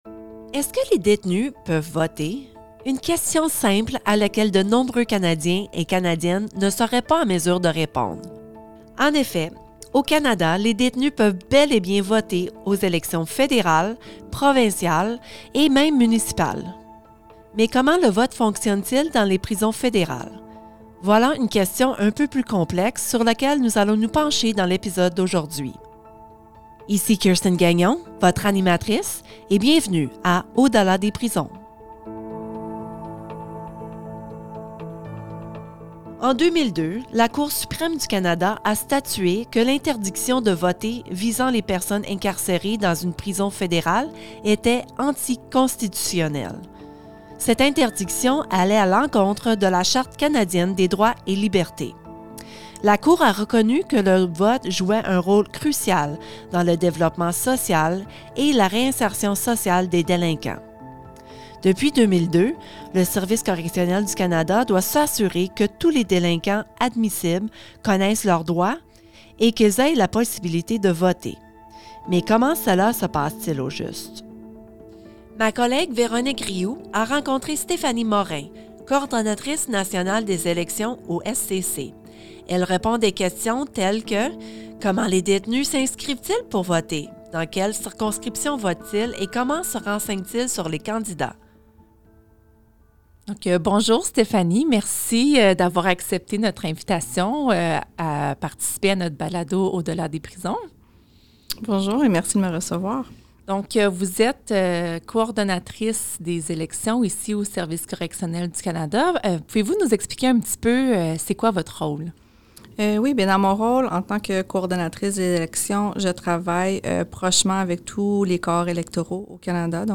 En 2002, la Cour Suprême du Canada a statué que d’empêcher les détenus de voter contrevenait à la Charte canadienne des droits et libertés. Dans cet épisode, des employés du Service correctionnel du Canada décrivent le fonctionnement du vote dans les prisons fédérales. Nous parlons aussi avec une personne incarcérée qui nous explique qu’est-ce que le droit de vote représente pour les détenus.